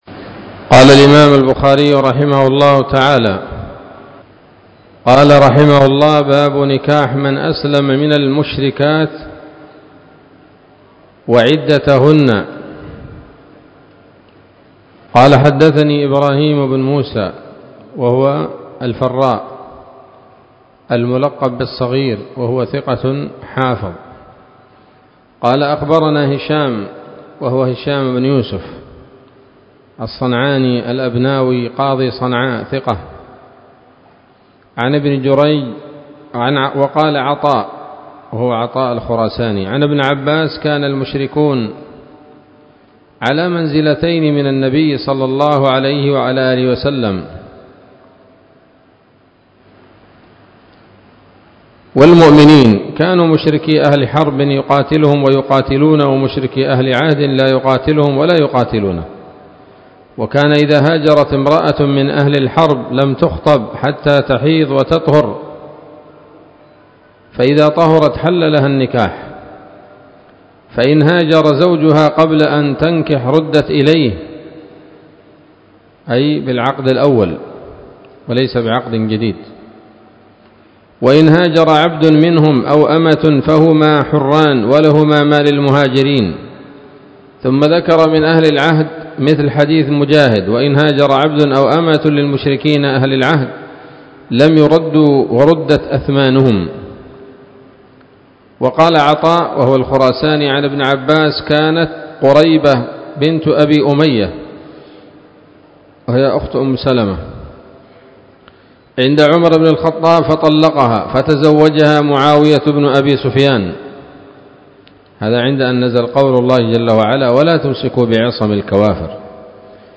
الدرس الخامس عشر من كتاب الطلاق من صحيح الإمام البخاري